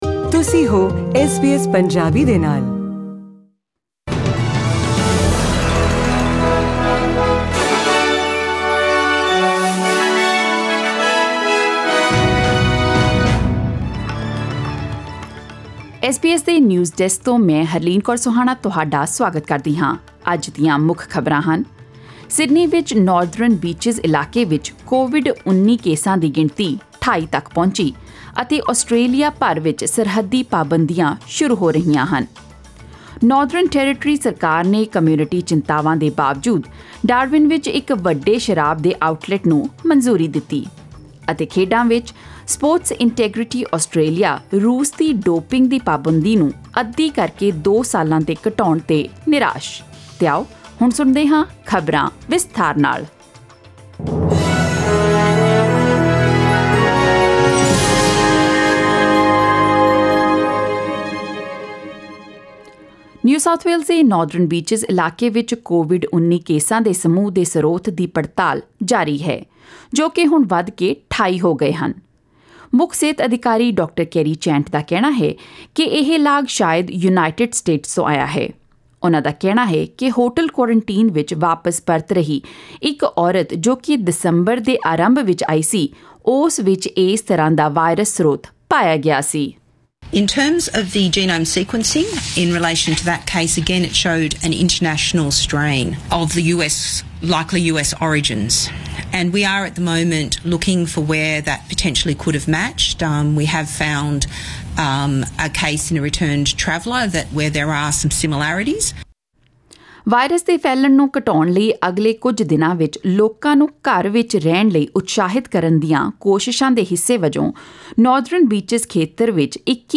Click the audio icon on the photo above to listen to the full bulletin in Punjabi Share